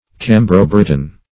Search Result for " cambro-briton" : The Collaborative International Dictionary of English v.0.48: Cambro-Briton \Cam"bro-Brit"on\ (k[a^]m"br[-o]*br[i^]t"[u^]n), n. A Welshman.
cambro-briton.mp3